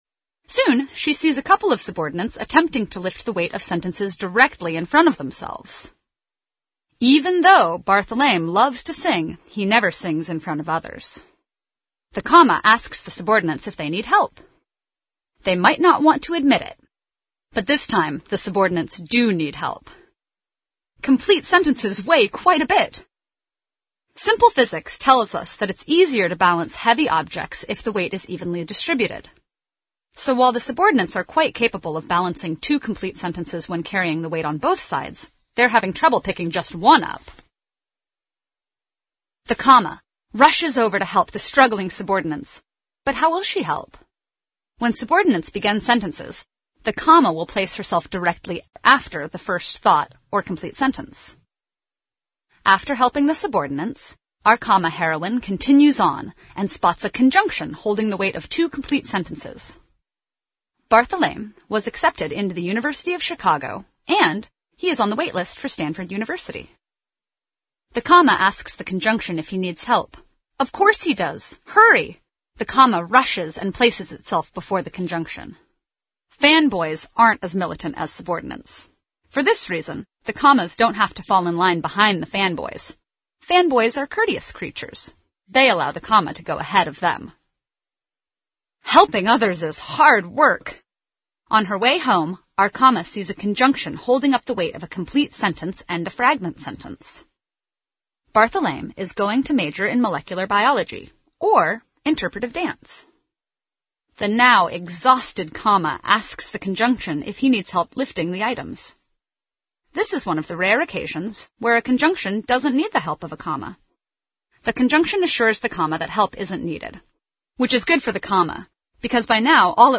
TED演讲:逗号的故事(2) 听力文件下载—在线英语听力室